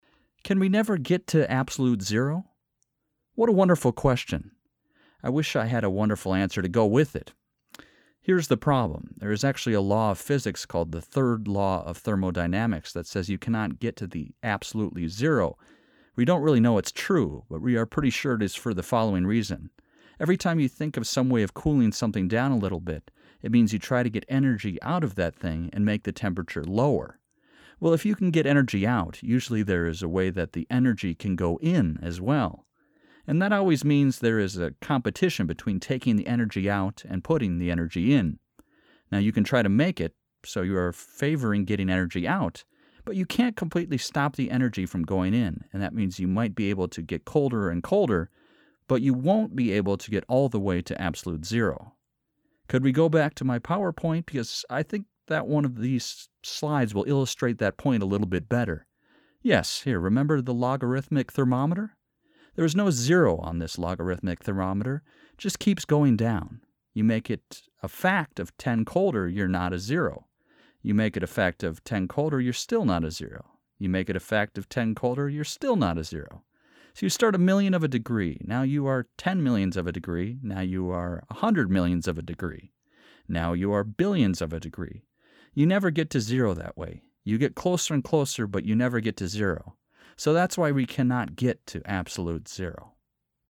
Retell Lecture - Absolute Zero PTE